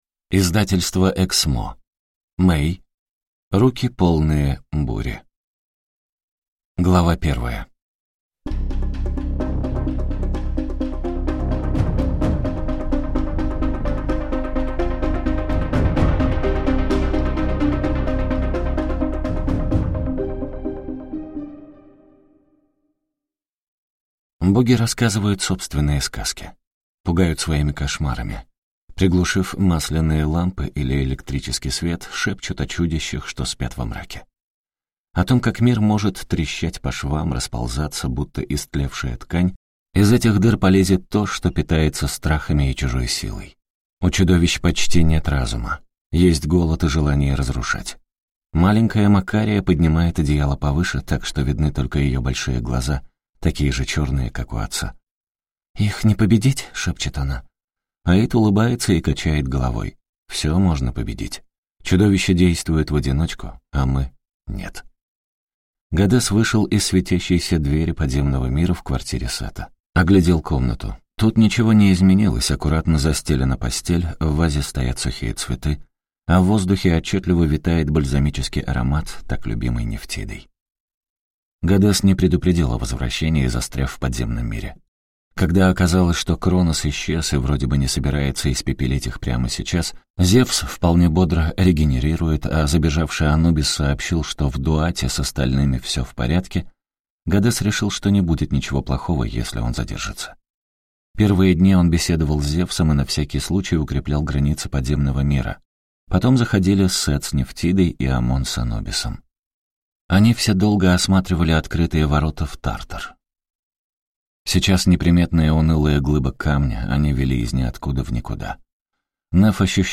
Аудиокнига Руки, полные бури | Библиотека аудиокниг